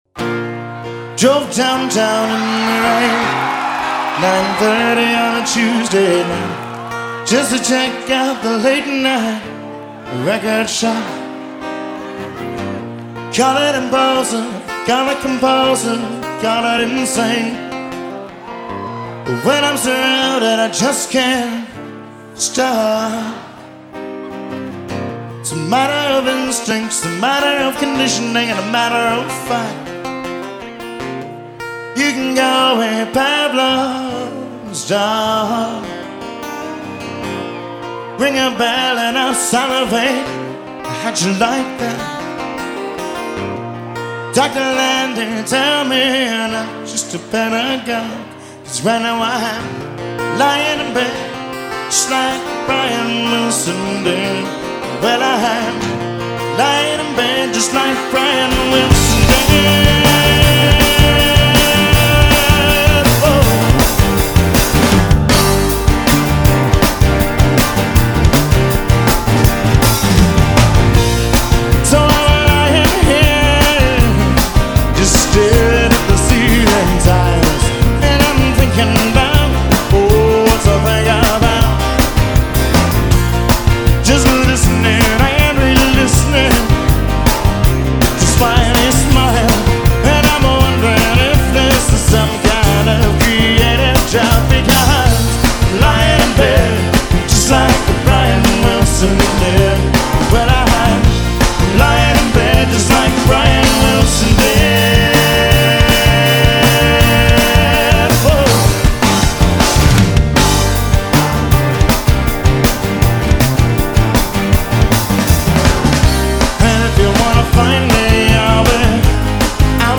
Here is the live version you may not have heard before.